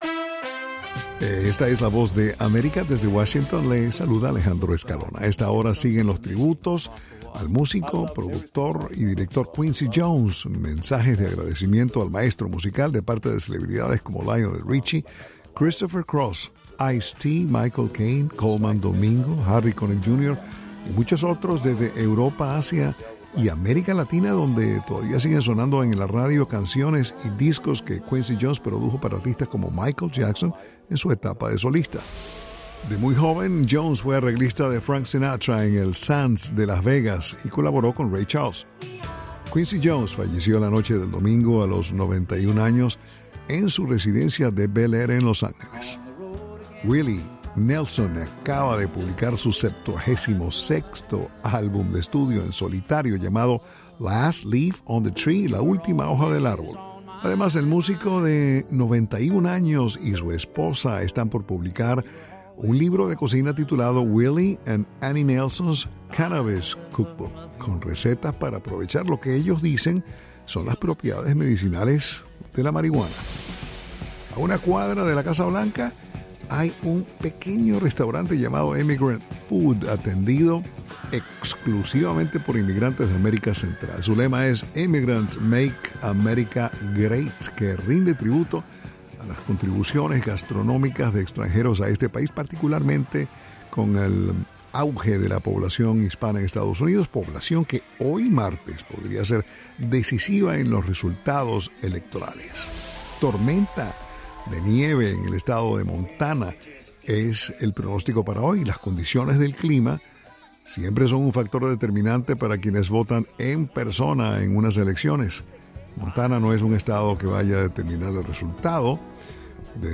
noticias del espectáculo